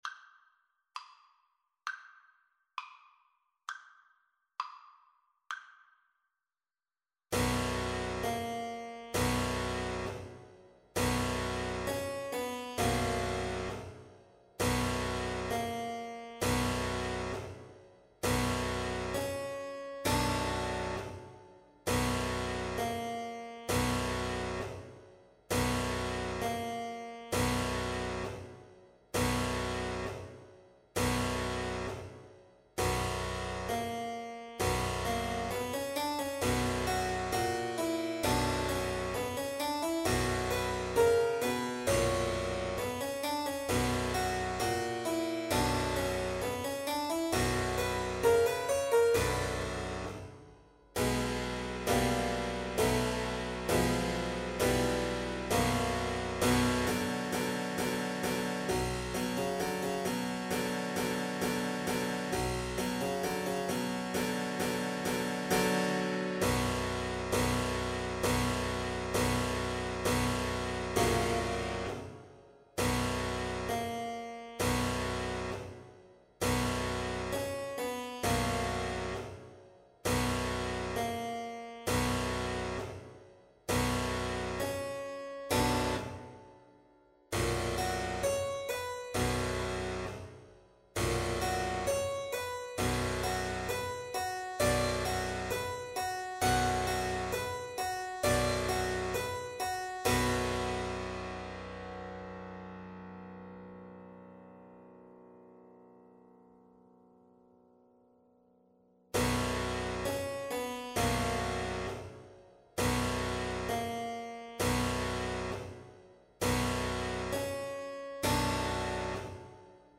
2/2 (View more 2/2 Music)
= 200 Allegro molto (View more music marked Allegro)
Classical (View more Classical Violin Music)